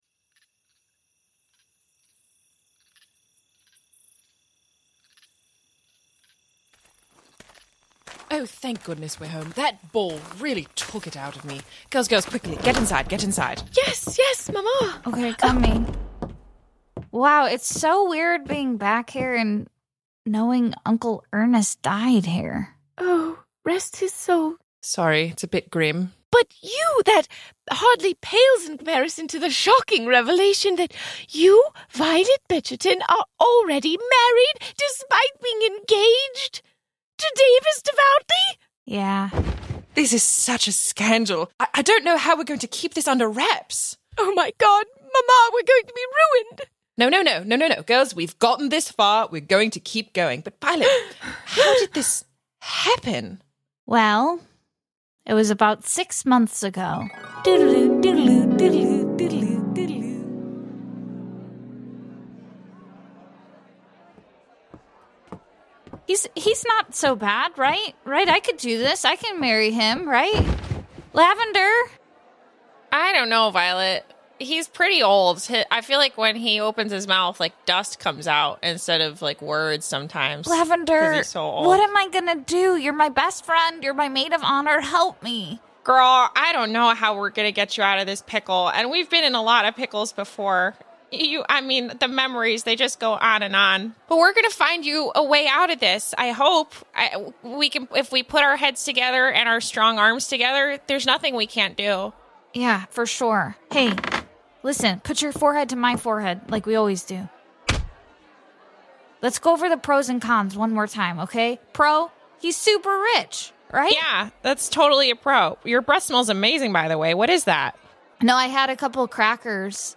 An improvised comedy podcast satirizing the world of Jane Austen that tells the tale of a wealthy family's scandals and shenanigans.